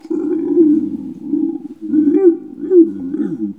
bruit-animal_06.wav